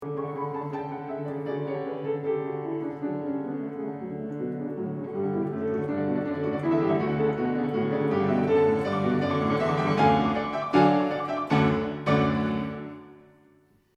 To finish the piece, Beethoven kind of rolls the music like a snowball rolling down a hill getting larger and larger and then Bang!, we’re at the end.